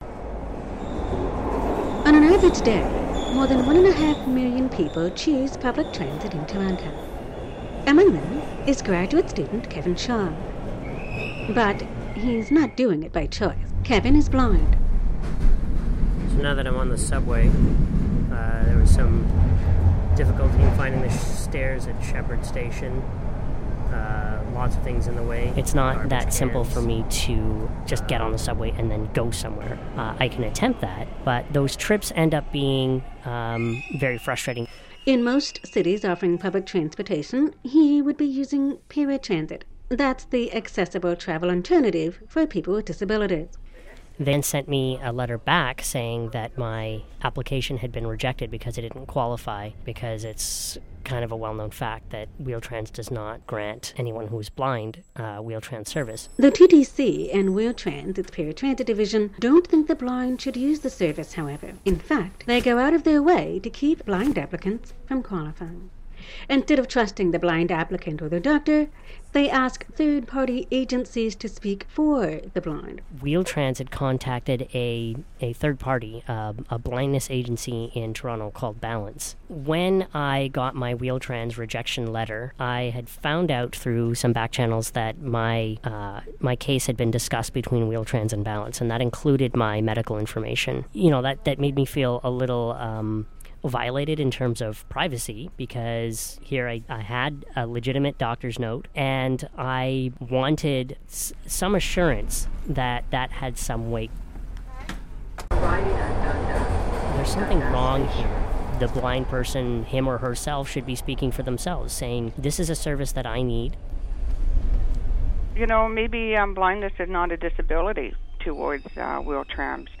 TTC - Wheel-Trans Verses The Blind presents the human side of an ongoing standoff between the Toronto transit Commission's paratransit service and a community who, one would think, automatically qualified. A shorter version of this story aired on Canada's national Groundwire community news program.